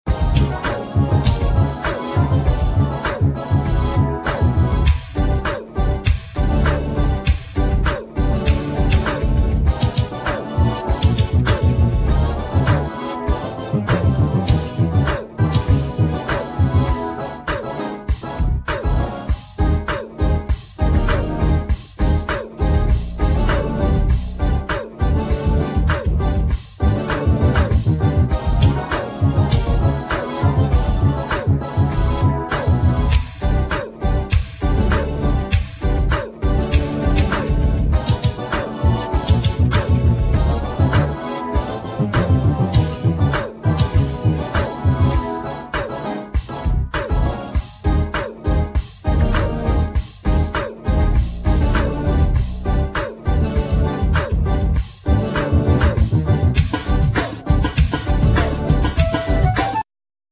Vocal,Synthsizer,Harmonica
Drums,Keyboards
Guitar,Mandlin
Bass,Melodica